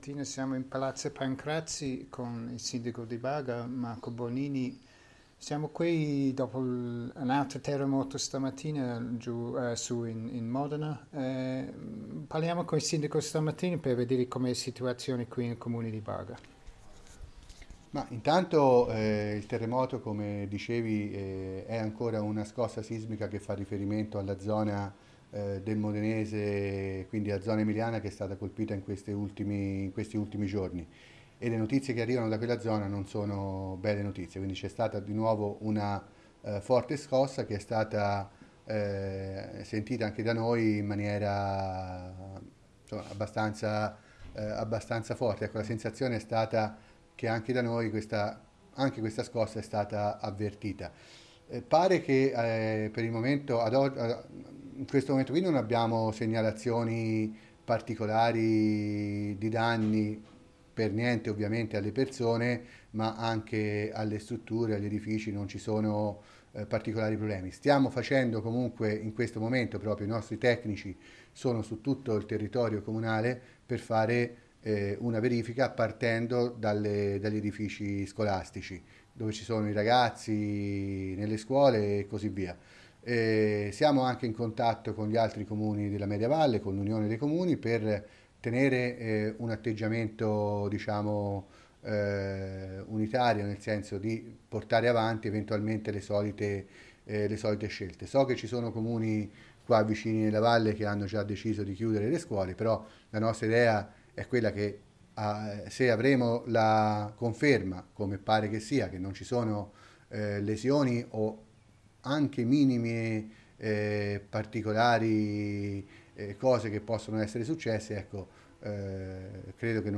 As the Mayor of Barga, Marco Bonini says in the interview recorded in Palazzo Pancrazi 10 minutes ago says, once these checks have been completed, the pupils and staff can once again re- enter the buildings and continue their studies.